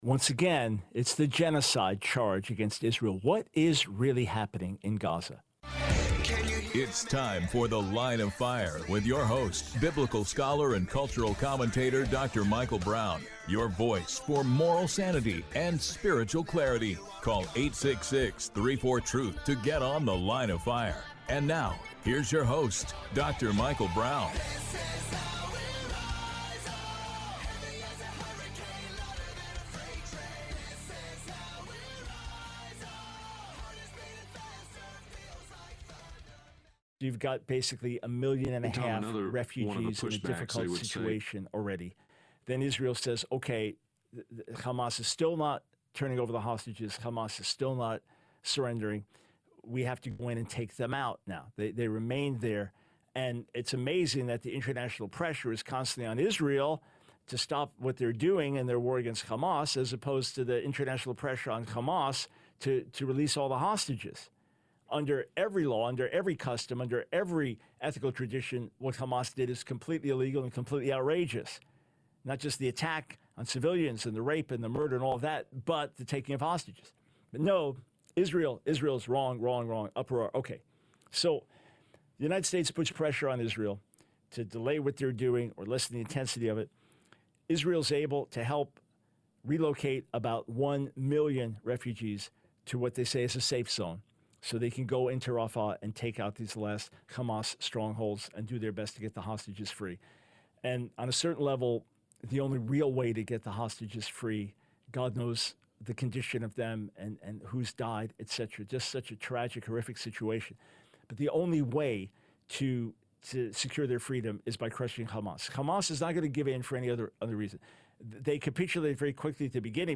The Line of Fire Radio Broadcast for 05/30/24.